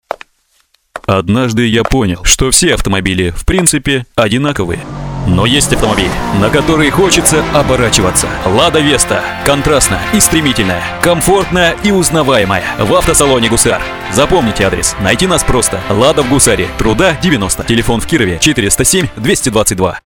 Радиоролик